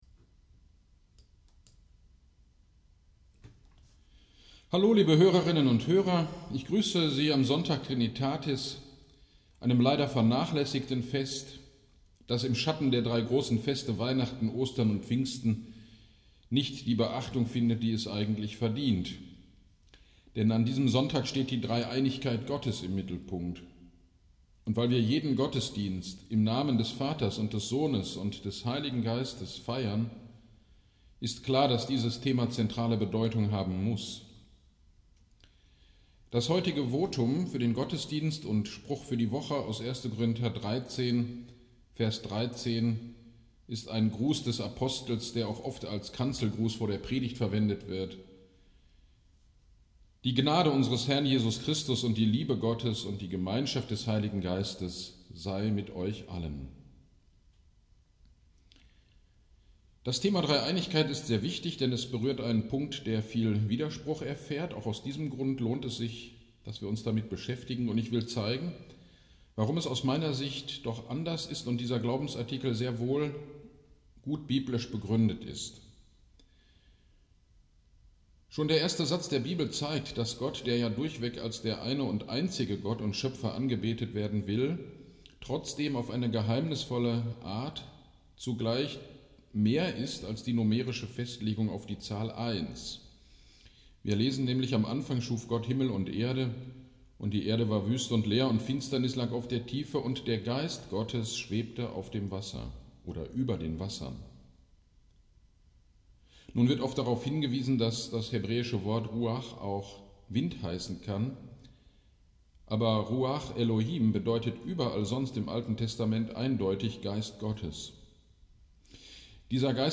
Gottesdienst am 30.05.2021 (Trinitatis) Predigt: Johannes 3,1-10